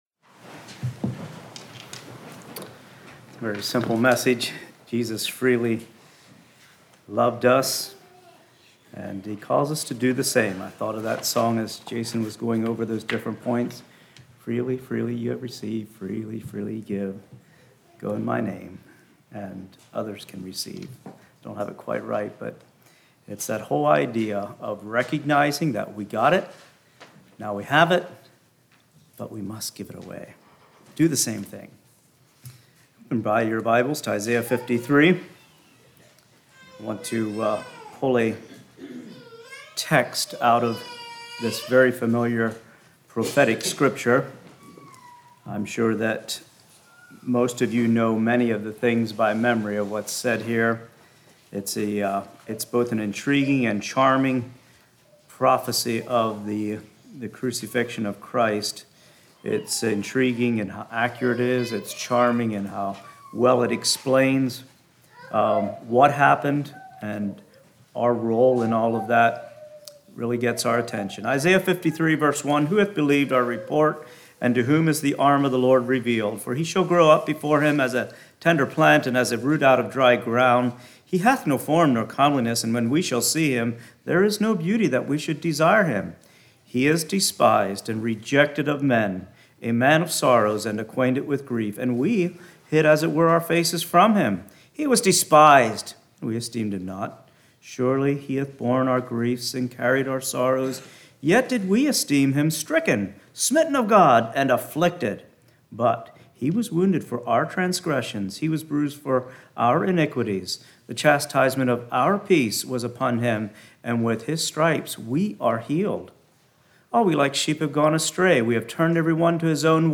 Play Now Download to Device The Silence of Surrender Congregation: Tyrone Speaker